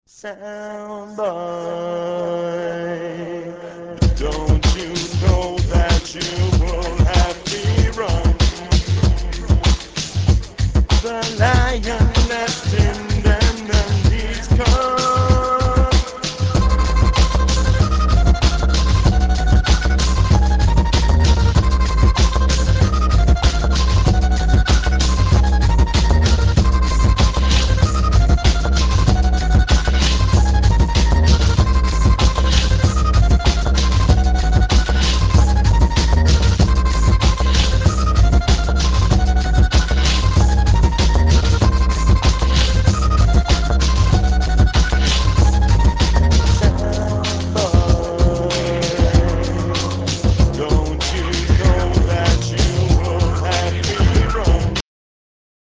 Dub, Downbeat und Drum&Bass